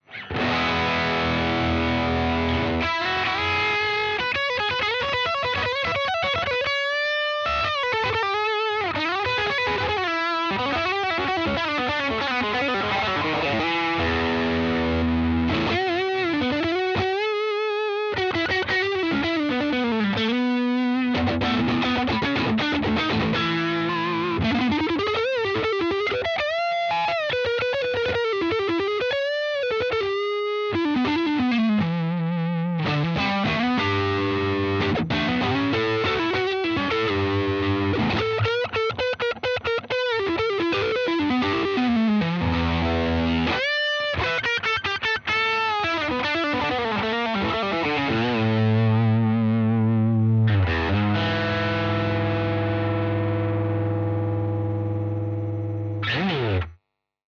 Égérie kit micros HepCat Broadcaster toutes positions saturé